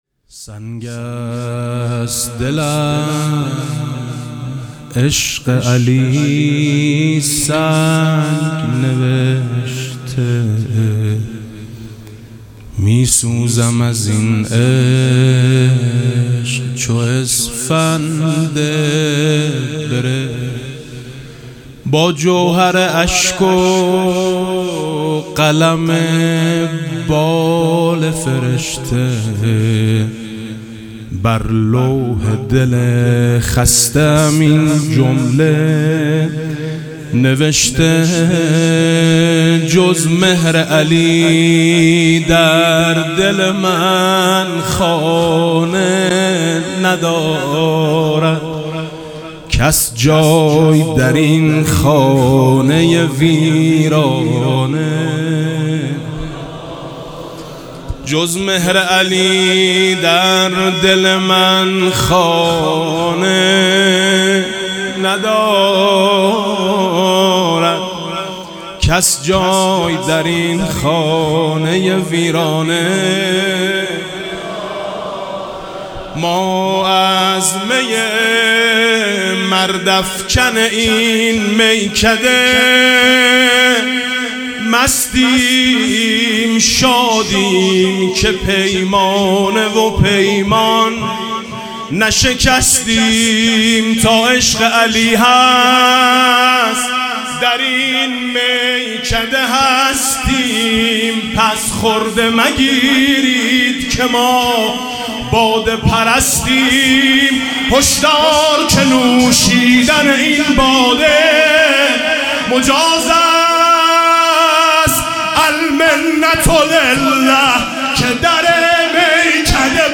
مدح: جز مهر علی در دل من خانه ندارد